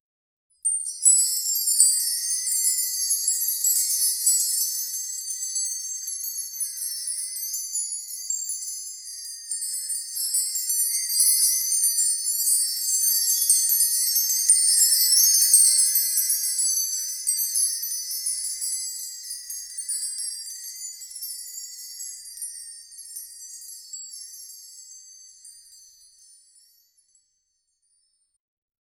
a-beautiful-shimmering-cr-jd7eqab3.wav